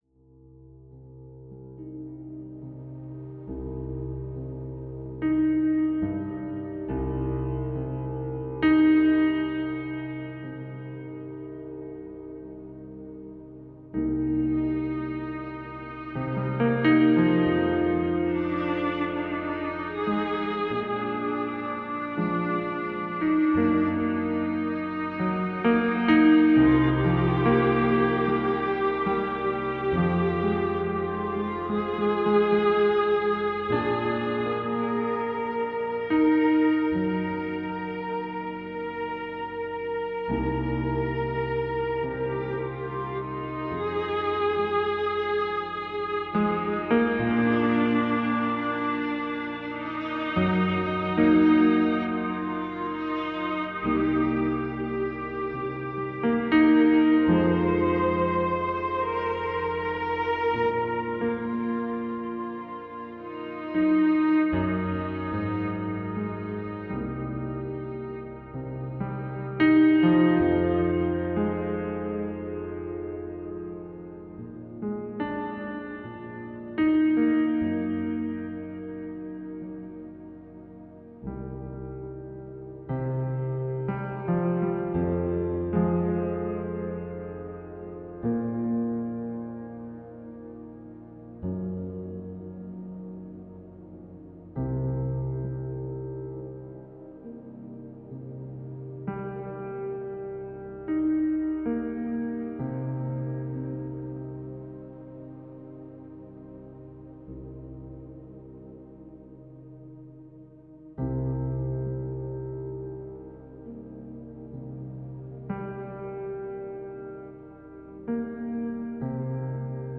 Musik zur Untermalung des Hörbuches